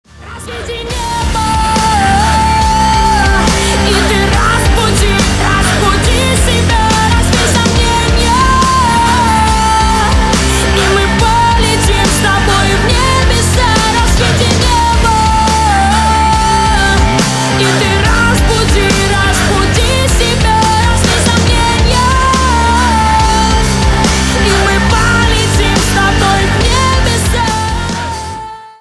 • Качество: 320, Stereo
громкие
Rap-rock
Рэп-рок
сильный голос